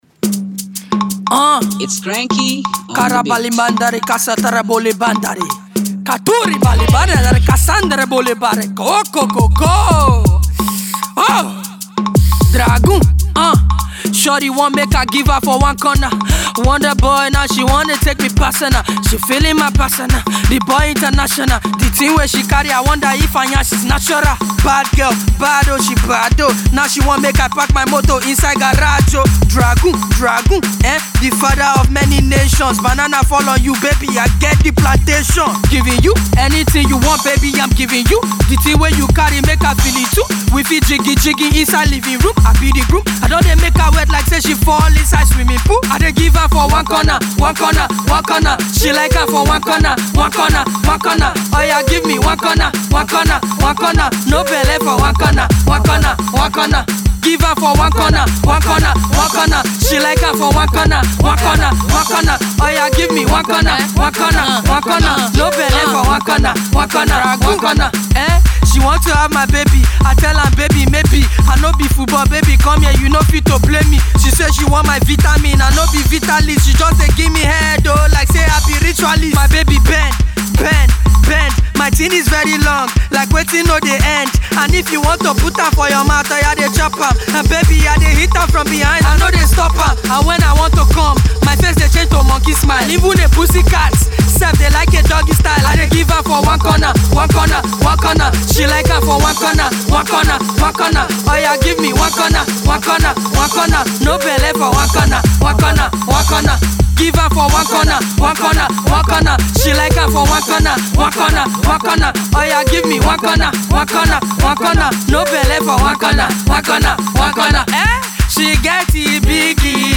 covers
with his great freestyle skill.